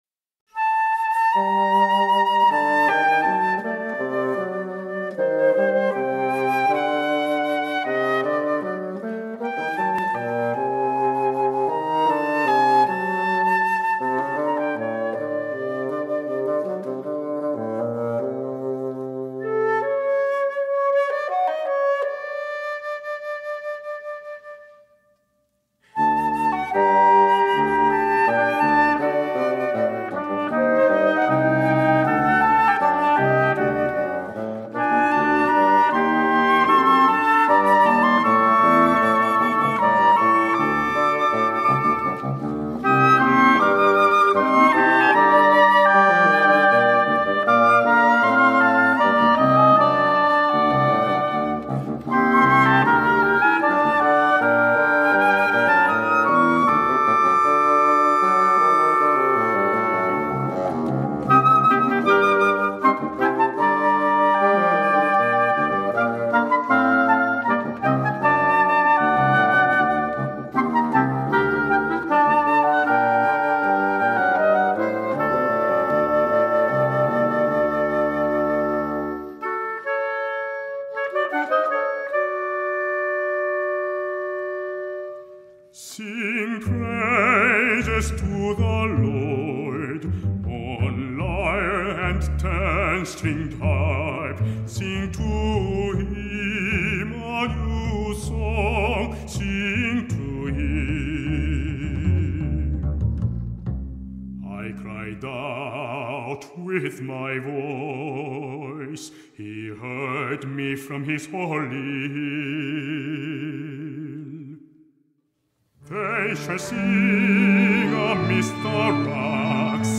an operatic baritone